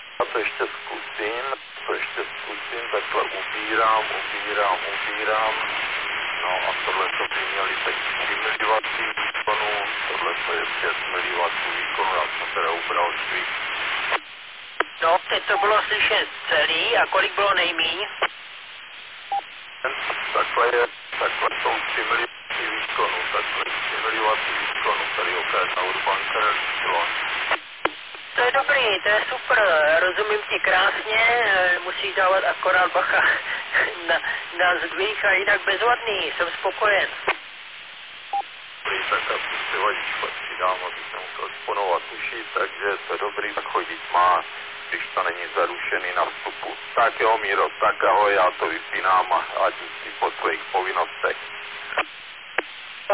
Suchý vrch 995 m.n.m. Orlické hory.
sumovy test.mp3